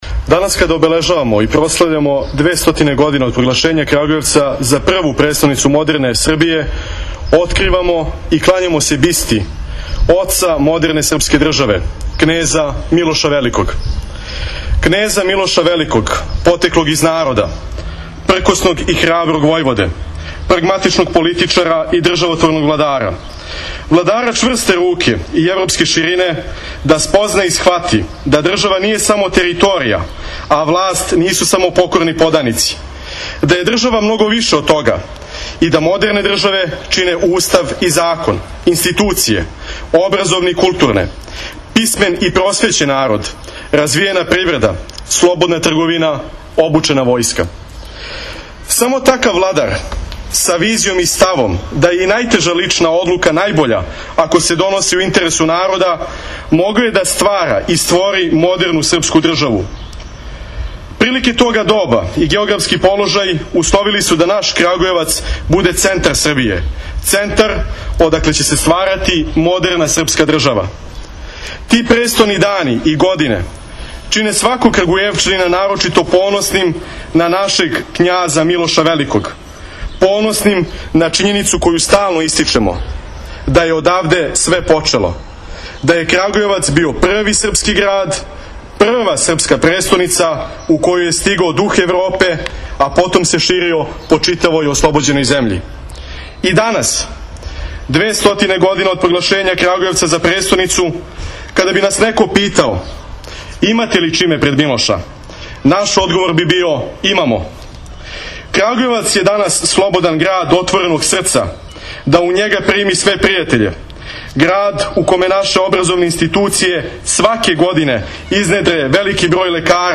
На 200. годишњицу од проглашења Крагујевца, првом српском престоницом модерне Србије, унутар Милошевог венца, код Амиџиног конака, у дворишту Народног музеја, јуче је баш на Ђурђевдан, откривена биста Милошу Обреновићу. У присуству представника локалне самоуправе, институција и гостију који су дошли да заједно са Крагујевчанима прославе 6. мај – Дан града и овогодишњи значајни јубилеј, бисту је открио градоначелник Крагујевца Радомир Николић, а окупљенима се обратио Мирослав Петрашиновић, председник Скупштине града.
Otvaranje-spomenika-knezu-Milosu-Miroslav-Petrasinovic.mp3